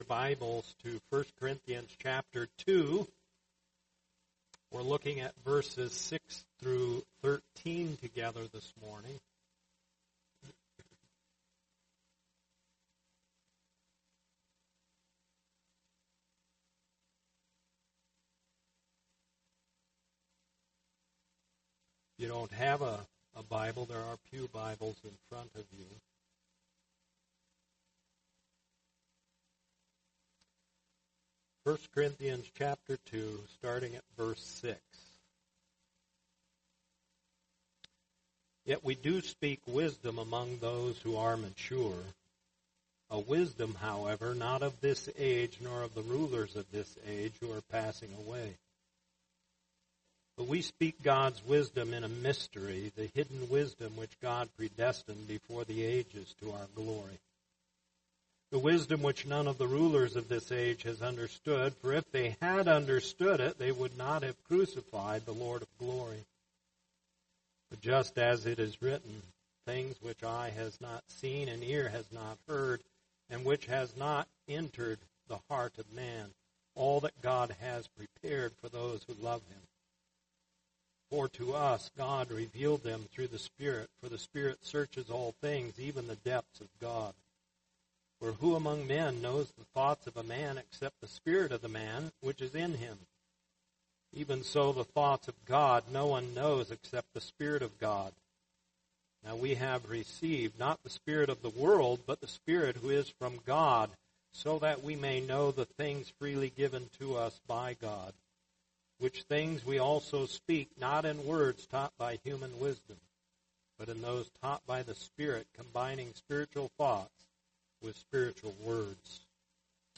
Sermons | Lake Phalen Community Church